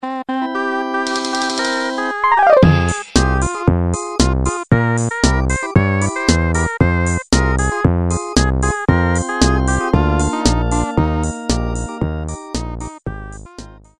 Siemens полифония. Шансон